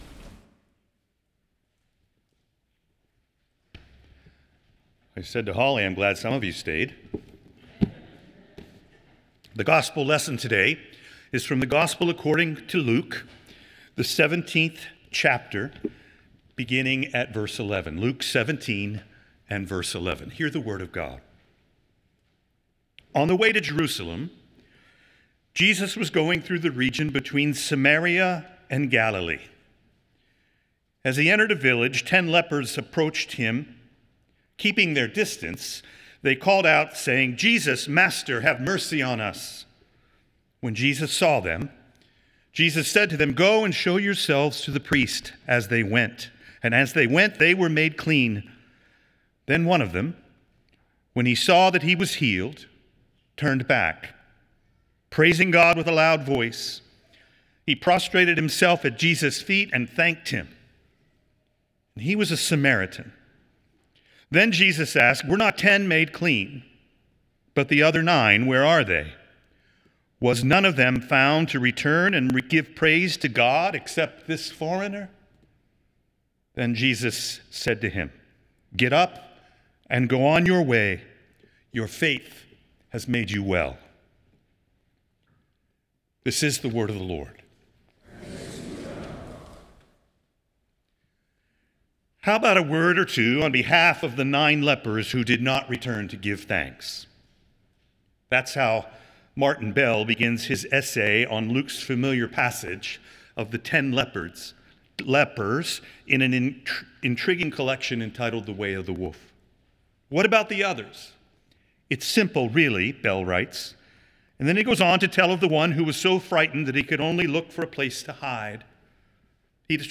Nassau Presbyterian Church Sermon